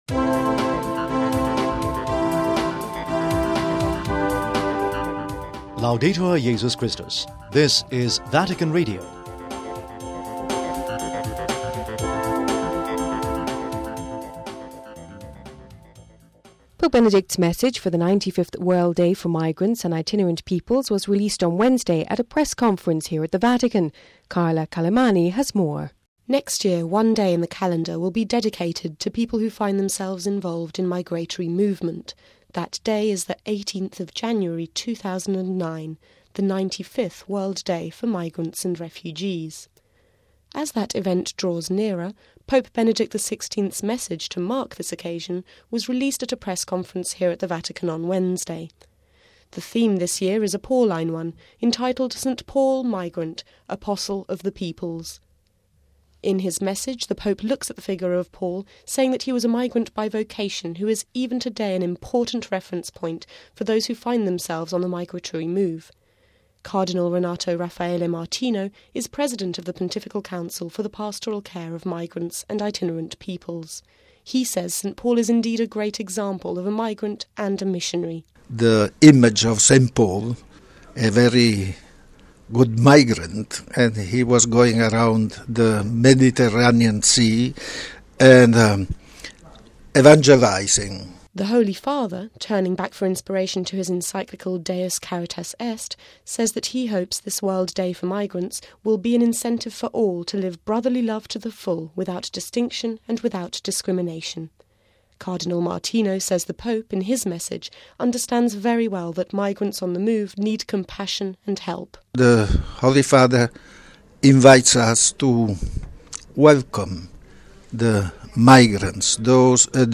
(08 Oct 08 - RV) Pope Benedict’s message for the 95th World Day for Migrants and Itinerant Peoples, was released Wednesday at a press conference here at the Vatican.